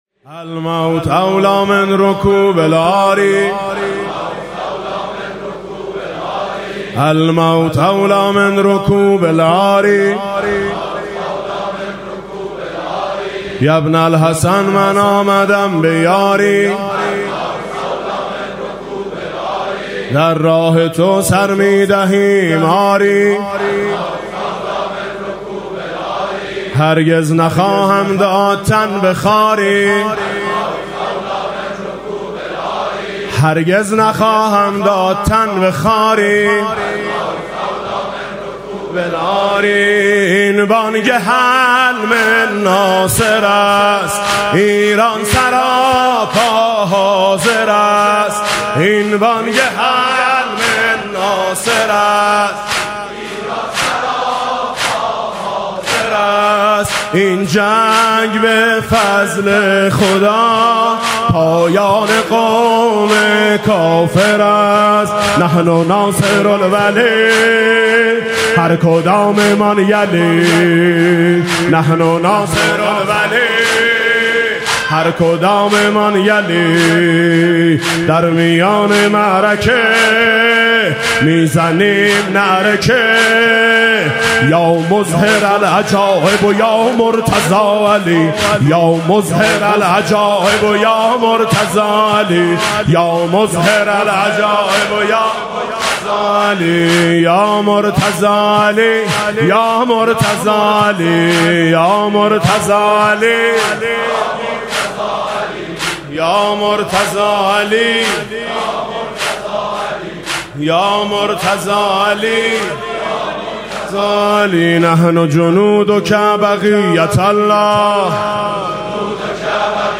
محفل عزاداری شب پنجم محرم هیأت آیین حسینی با سخنرانی حجت‌الاسلام رفیعی و بانوای میثم مطیعی در امامزاده قاضی الصابر (علیه‌السلام) برگزار شد.
مداحی